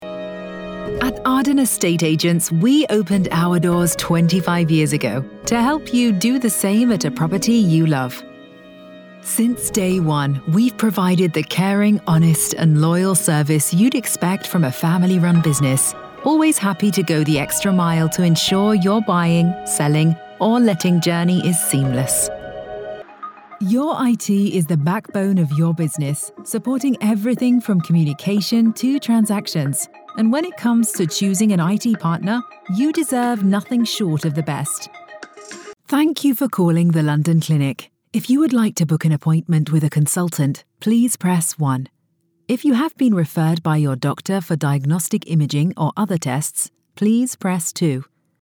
Deep, Natural, Distinctive, Warm, Soft
Telephony